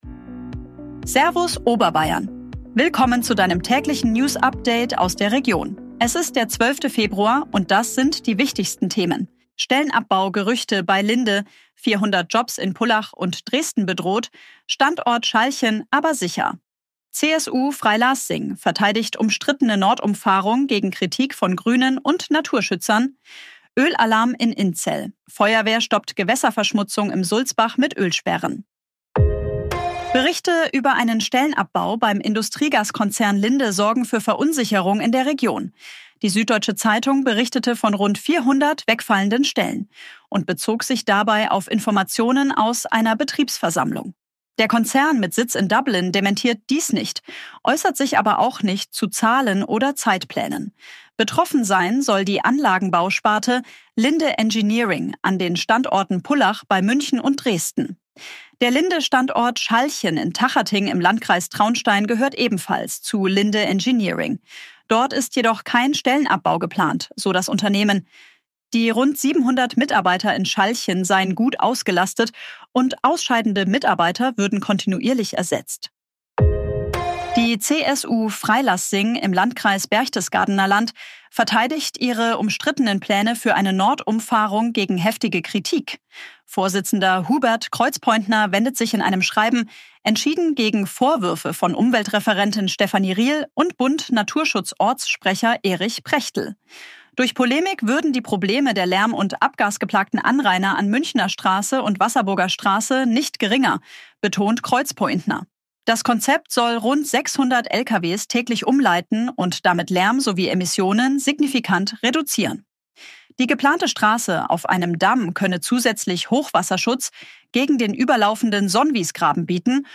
Dein tägliches News-Update
wurde mit Unterstützung künstlicher Intelligenz auf Basis von